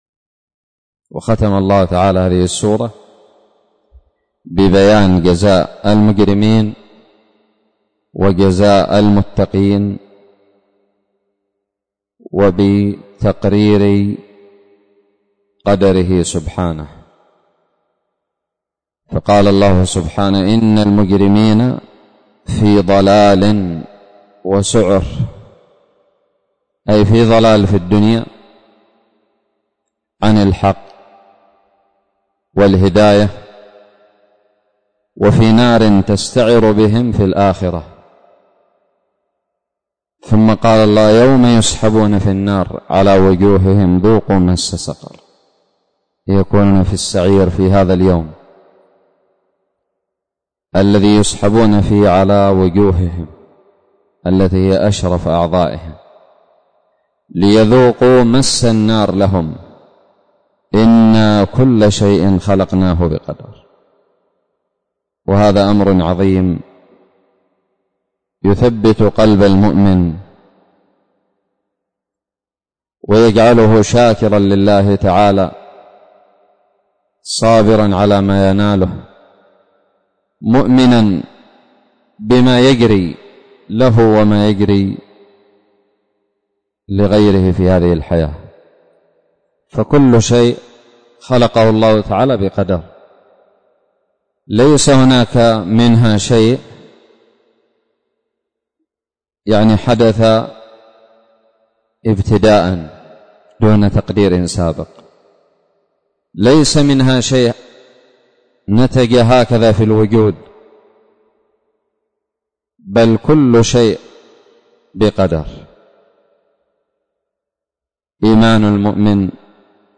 الدرس الثامن والأخير من تفسير سورة القمر
ألقيت بدار الحديث السلفية للعلوم الشرعية بالضالع